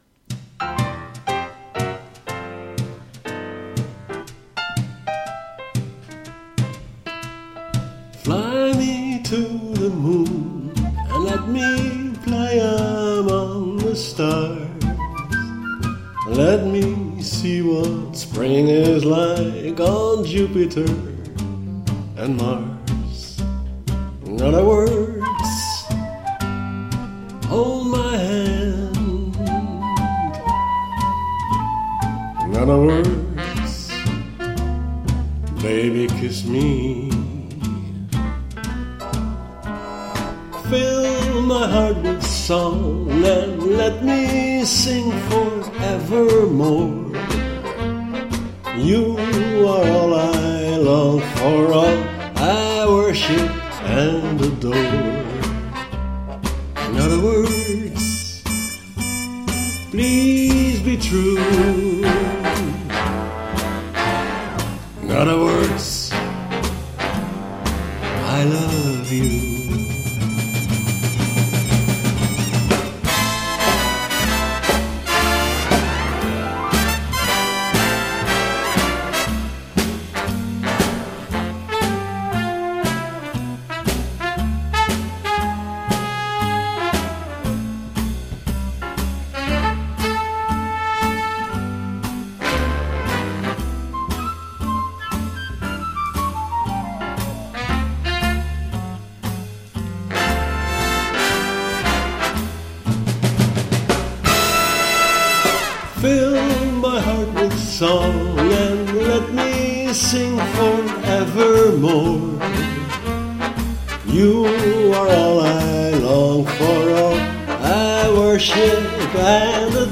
sung by me